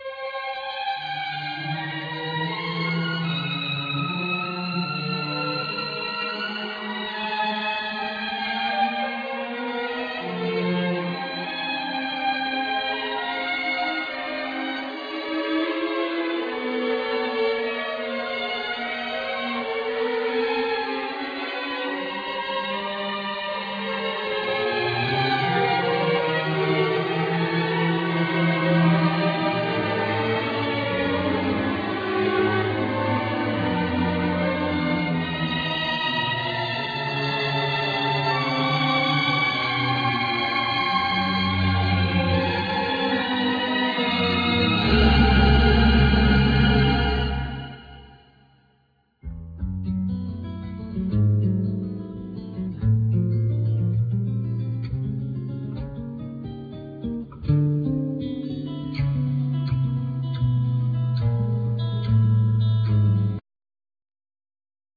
Keyboards,Piano
Bass
Drums
Guitar, Guitar-synth, Synthsizer, Arp Avatar